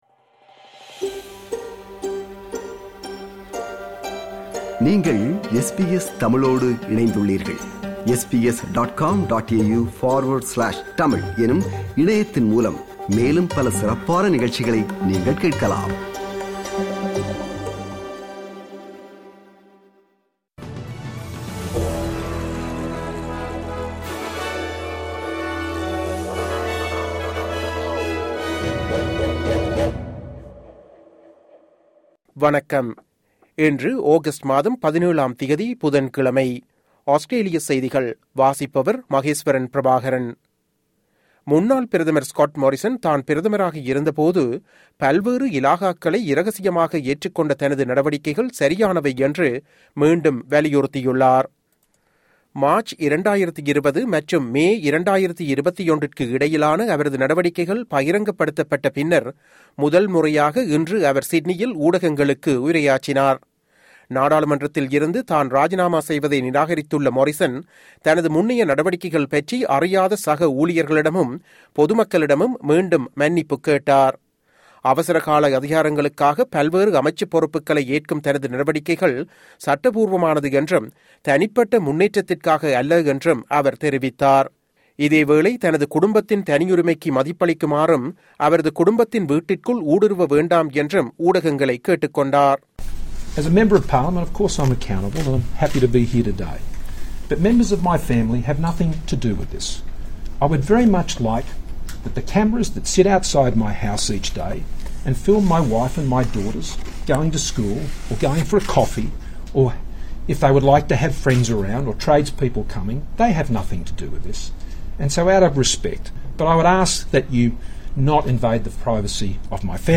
Australian news bulletin for Wednesday 17 August 2022.